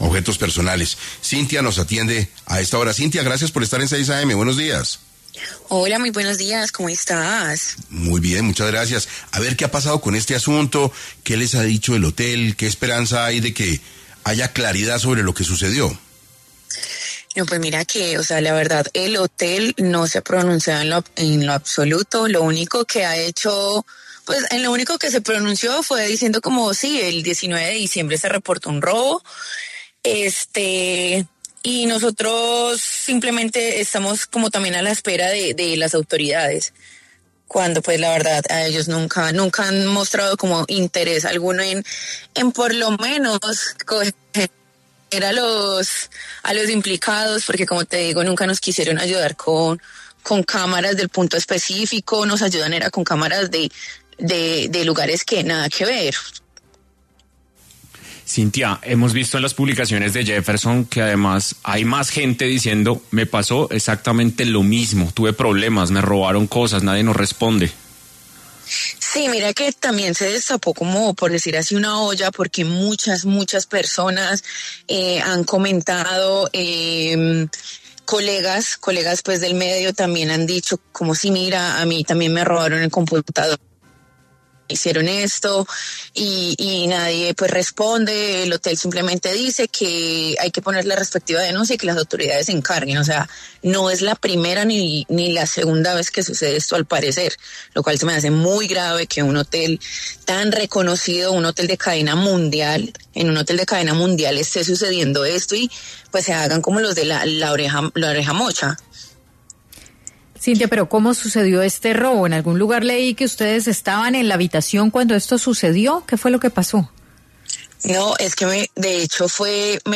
En diálogo con 6AM de Caracol Radio, Cintia Cossio, aseguró que desde que se presentó la denuncia correspondiente, el hotel no ha respondido por lo ocurrido y nunca han demostrado interés para esclarecer los hechos.